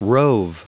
Prononciation du mot rove en anglais (fichier audio)